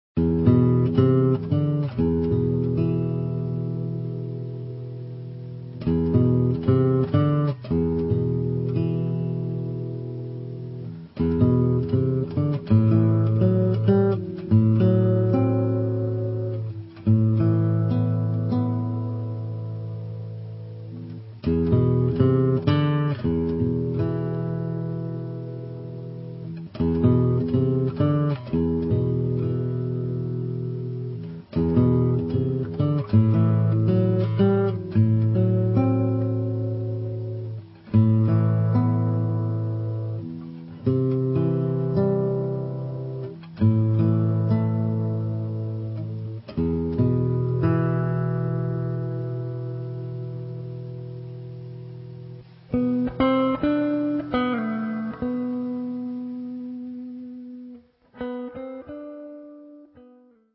Two instrumental suites.